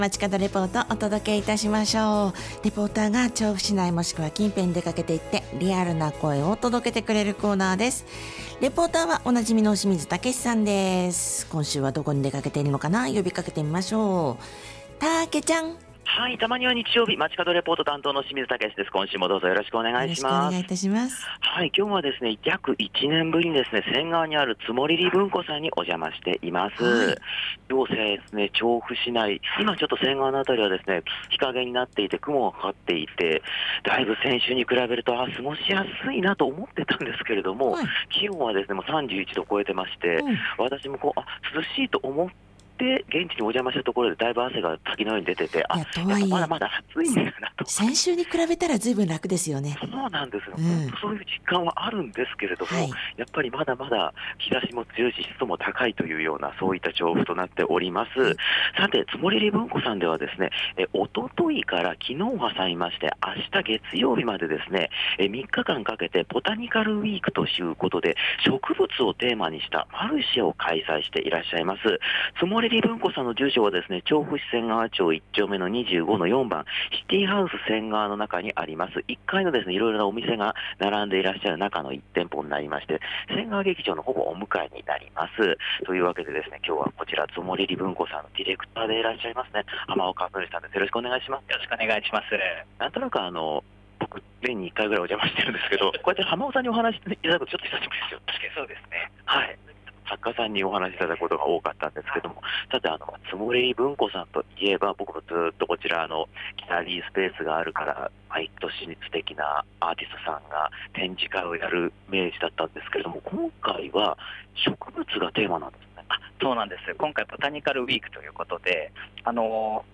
気温が下がり秋が近づいたと錯覚する様な空の下からお届けした本日の街角レポートは、『ツォモリリ文庫』さんにお伺いして、「ボタニカルウィーク」のレポートでした！！